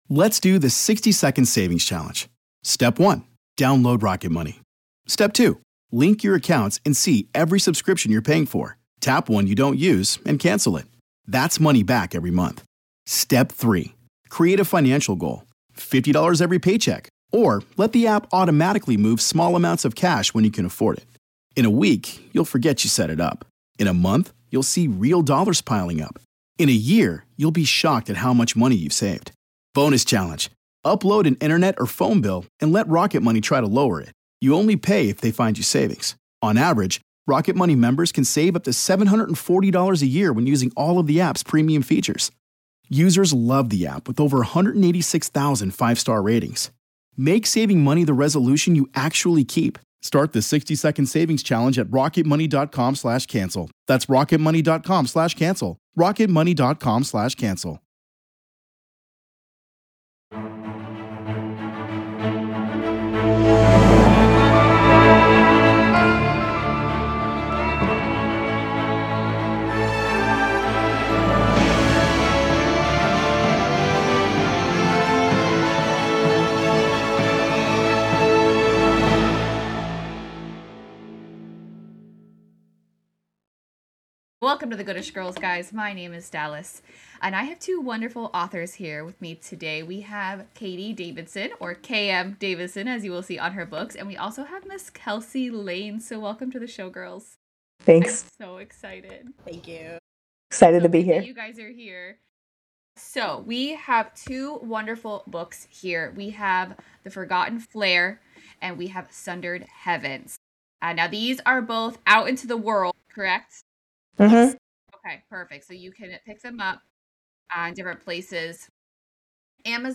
Listen in as we talk about dedications, scheduling in writing with your 9-5, character artwork and more in this episode!